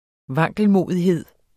Udtale [ ˈvɑŋgəlmoˀðiˌheðˀ ]